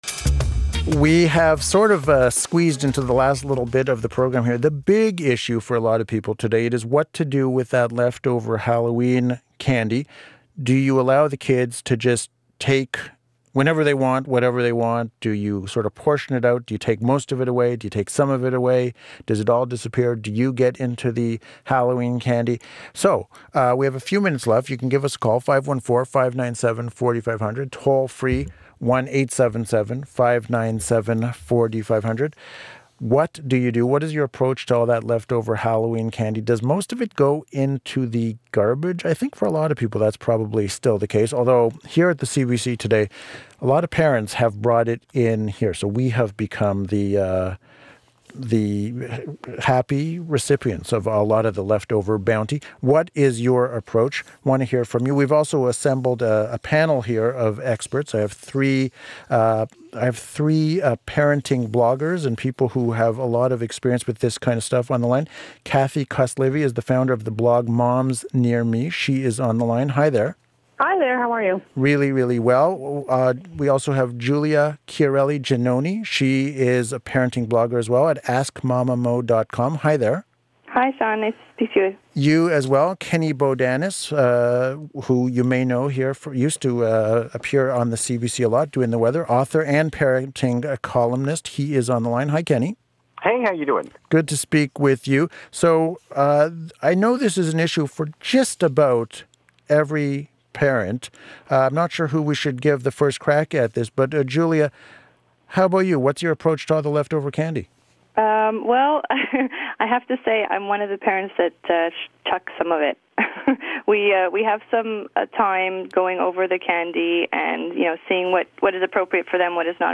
Our Segment on CBC Radio